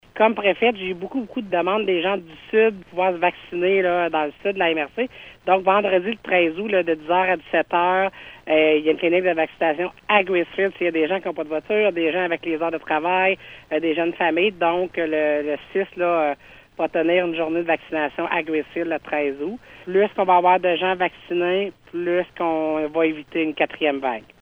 La préfète de la Vallée-de-la-Gatineau, Chantal Lamarche, estime que cette clinique répondra à un besoin chez plusieurs personnes pour qui il était plus difficile de se faire vacciner au centre de Maniwaki :